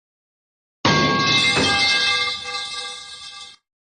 sfx_bump.mp3